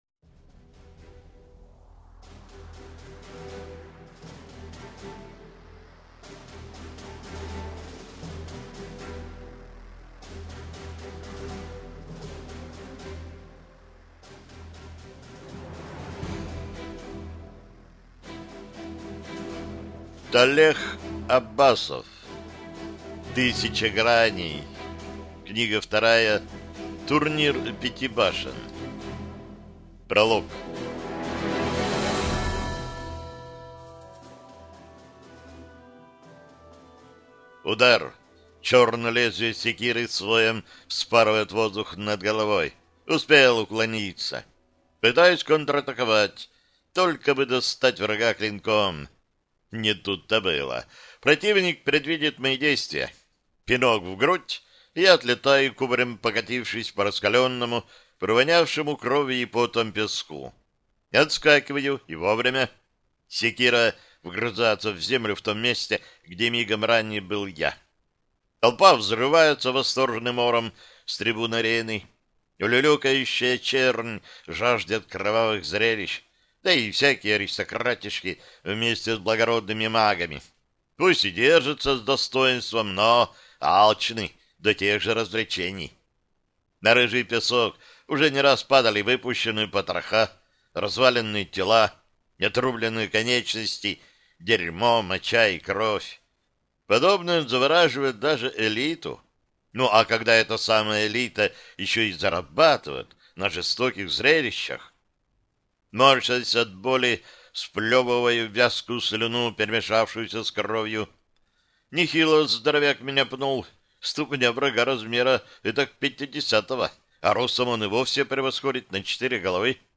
Аудиокнига Тысяча Граней 2. Турнир Пяти Башен | Библиотека аудиокниг